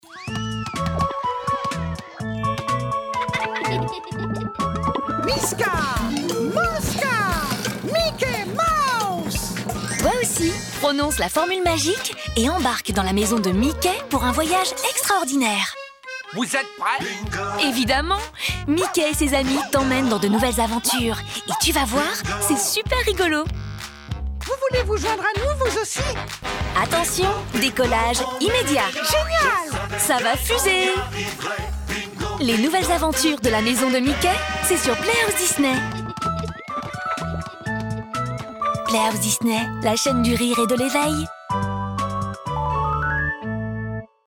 Bandes Annonces
Convaincant
Punchy
Voix off